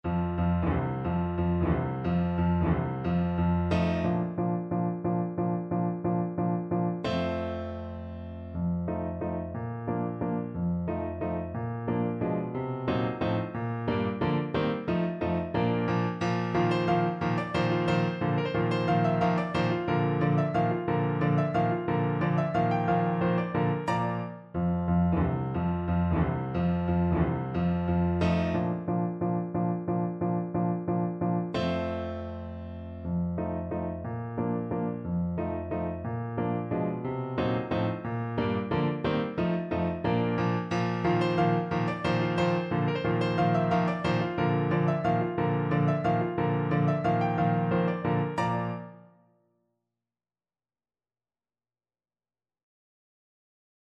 Chopin: Hulanka (na klarnet i fortepian)
Symulacja akompaniamentu